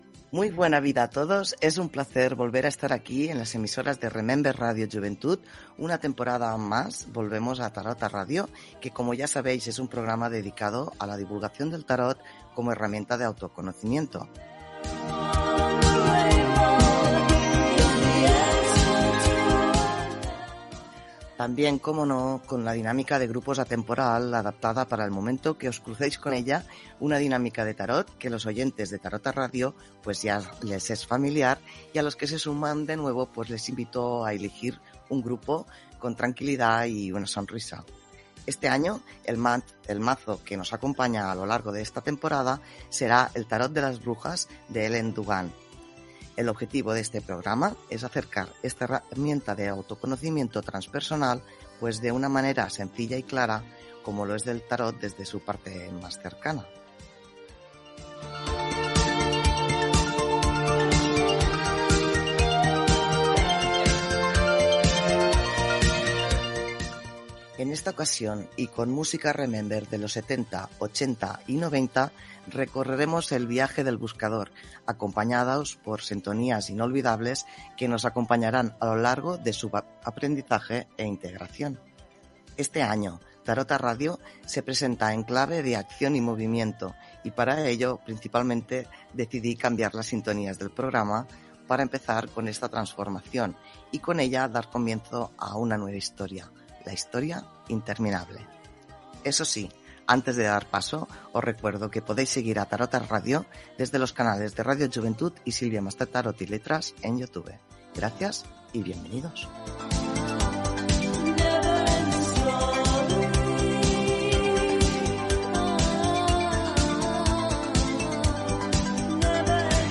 Identificació del programa, presentació, objectiu del programa, canvis i forma de contactar. Cita literària i tema musical.